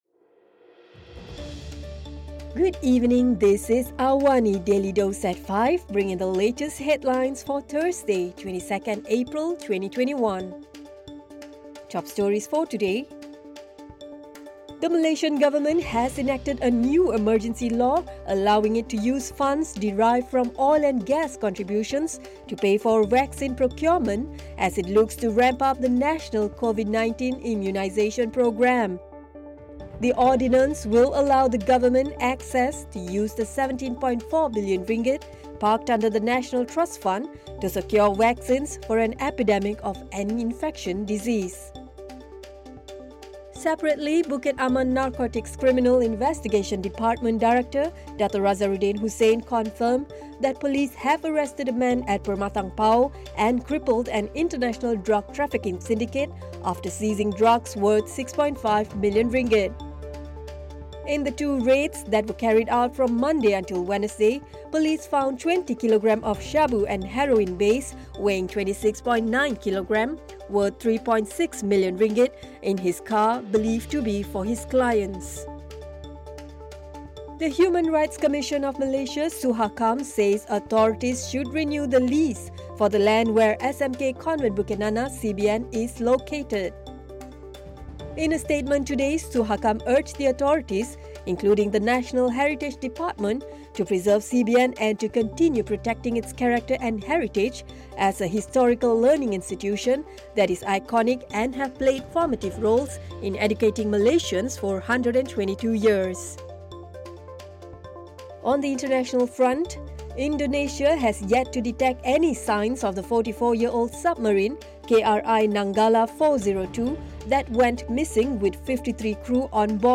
Listen to the top stories of the day, reporting from Astro AWANI newsroom — all in 3-minutes.